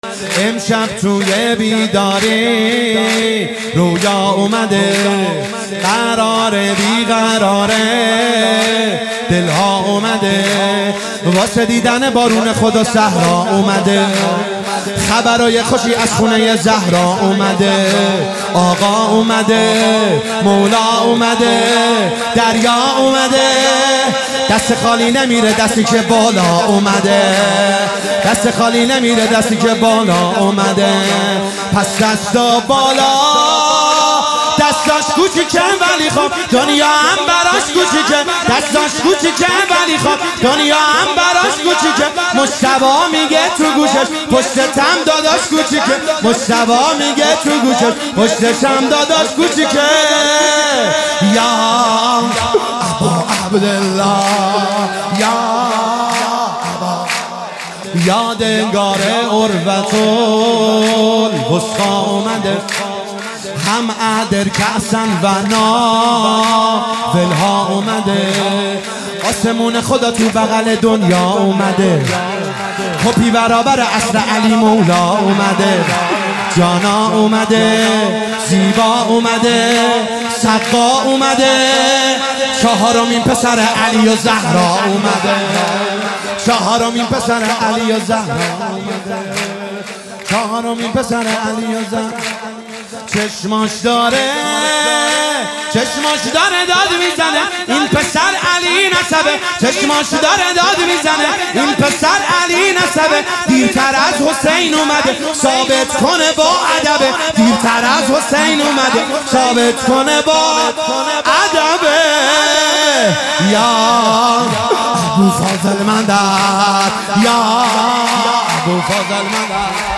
میلاد امام حسین علیه السلام 1400